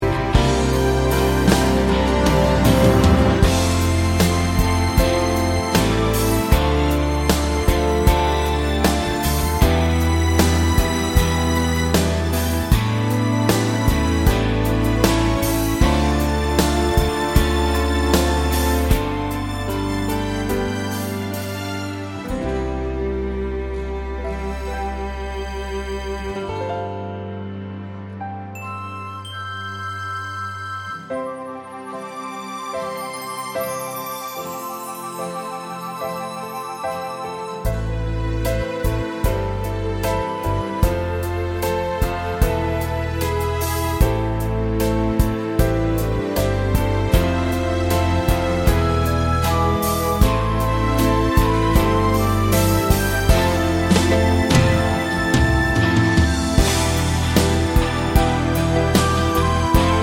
Live Medley Easy Listening 7:00 Buy £1.50